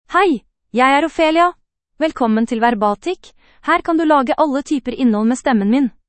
Ophelia — Female Norwegian Bokmål AI voice
Ophelia is a female AI voice for Norwegian Bokmål (Norway).
Voice sample
Listen to Ophelia's female Norwegian Bokmål voice.
Female